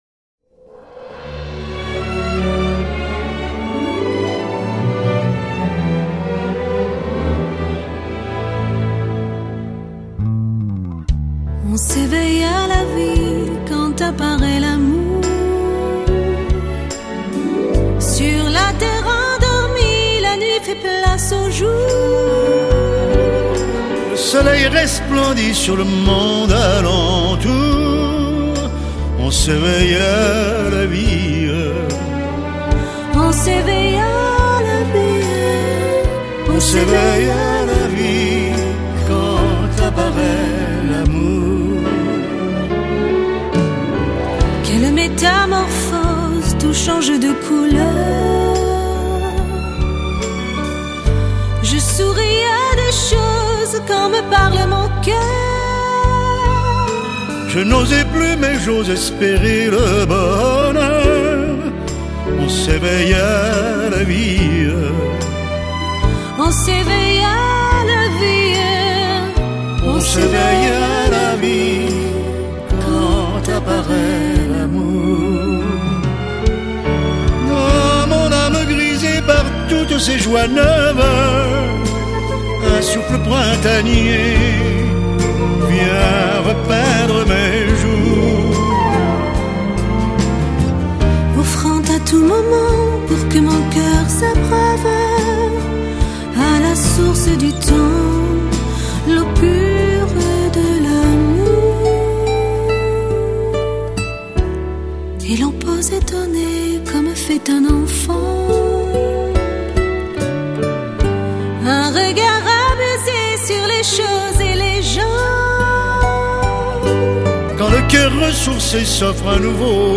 gezongen in duet